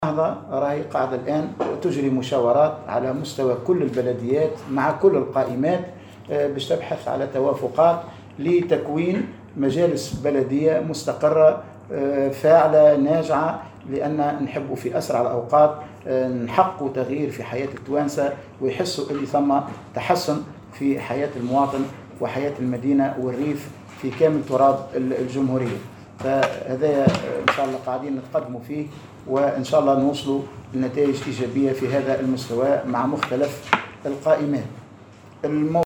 وقال في تصريح لمراسلة "الجوهرة أف أم" على هامش انعقاد الدورة العشرين لمجلس شورى حركة النهضة، أن الحركة تتطلع لبلوغ نتائج ايجابية مع مختلف القائمات لتحقيق تغيير في حياة المواطن في أسرع الأوقات.